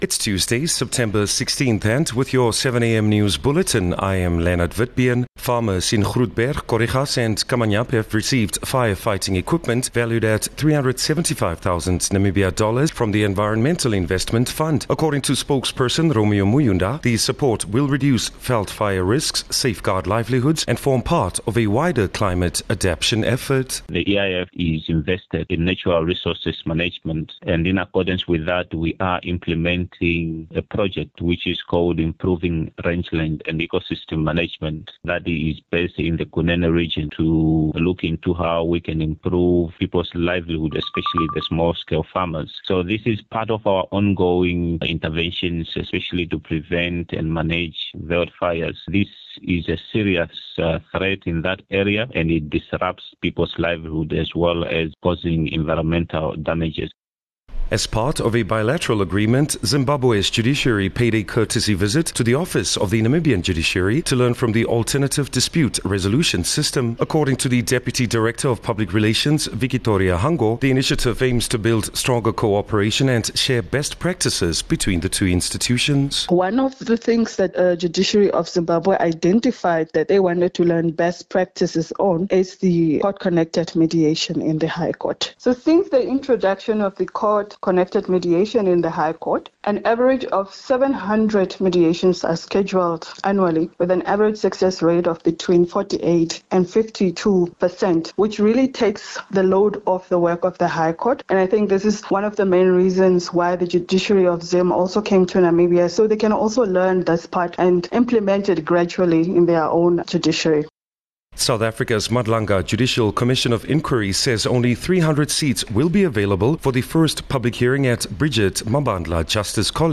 16 Sep 16 September-7am news